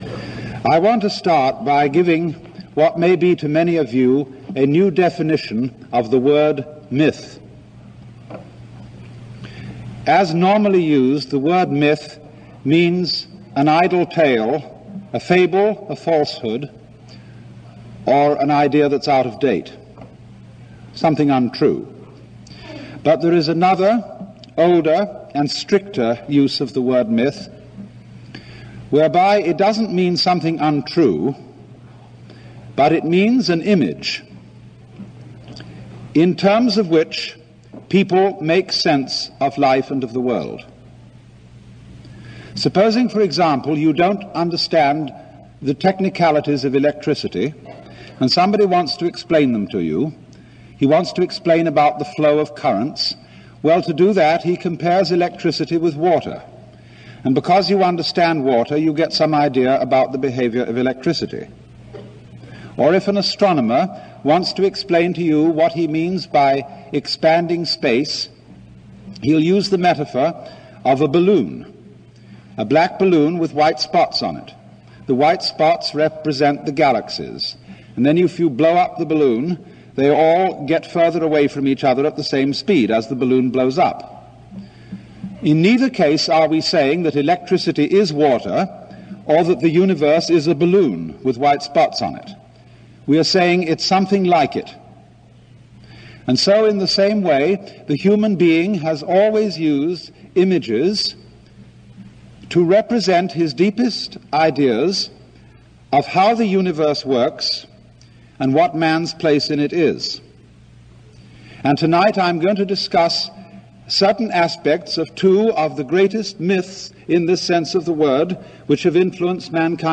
We've briefly discussed it in Meanderings 8 but you can more fully appreciate it via the lucid and thorough exposition that Alan Watts gives in his 48-minute lecture entitled The Image of Man